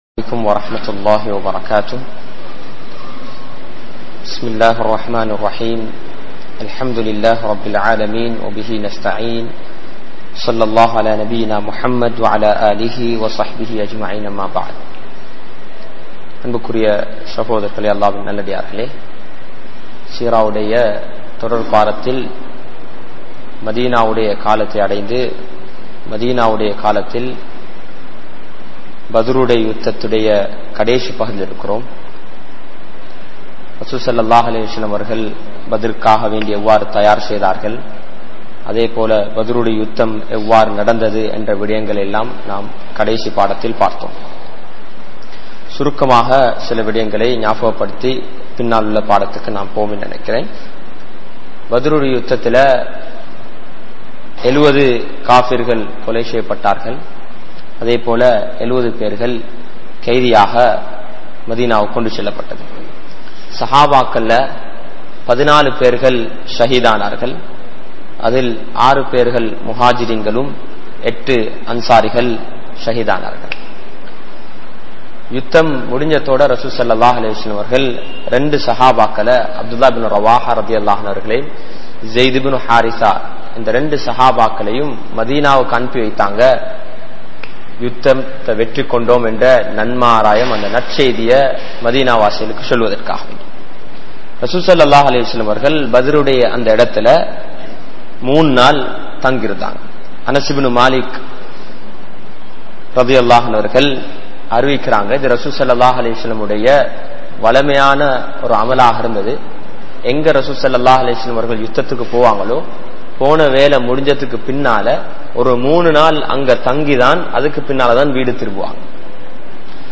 Seerah Lesson | Audio Bayans | All Ceylon Muslim Youth Community | Addalaichenai